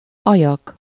Ääntäminen
IPA : /ˈlɪp/ US : IPA : [ˈlɪp]